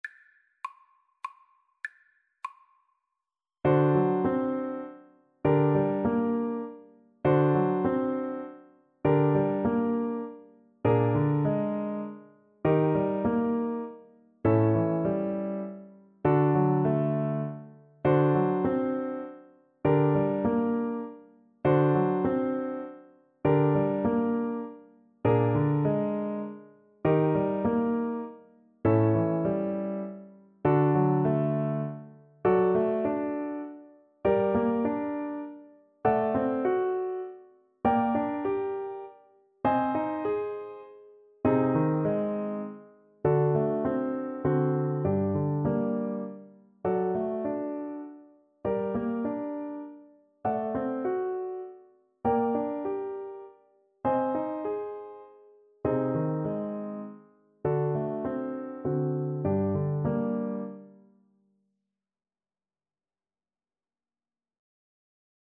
Etwas bewegt
3/4 (View more 3/4 Music)
Classical (View more Classical Clarinet Music)